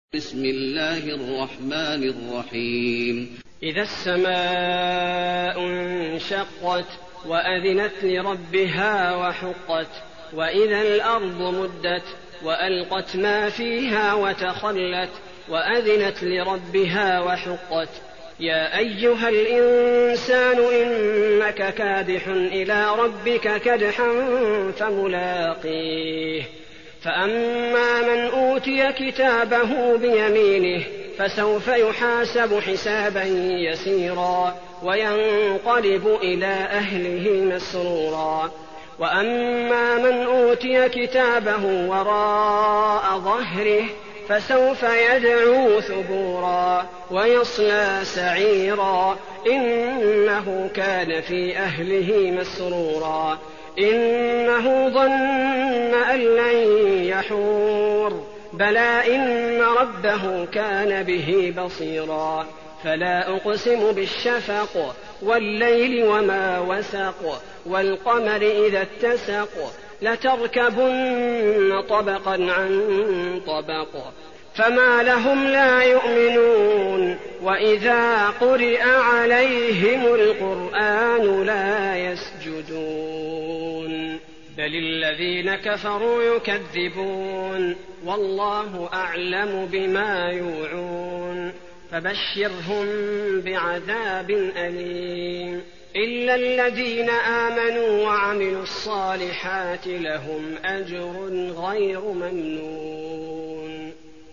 المكان: المسجد النبوي الانشقاق